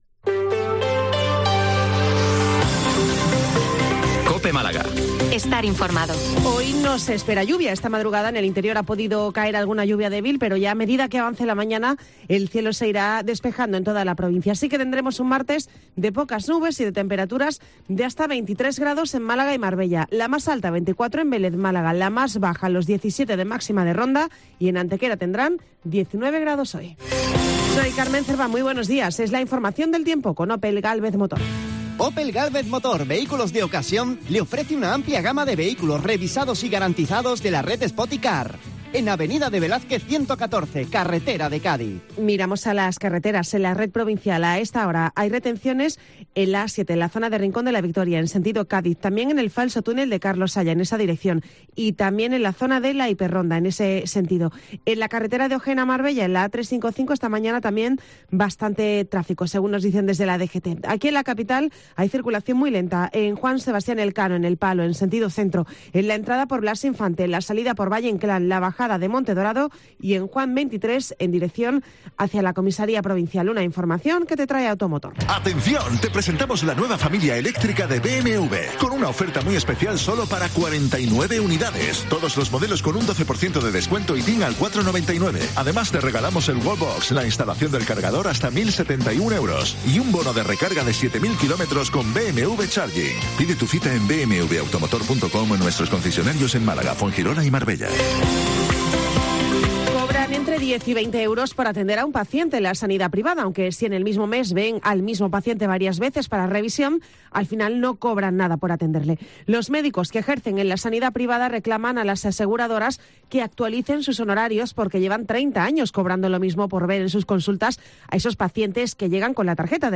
Informativo 08:24 Málaga-241023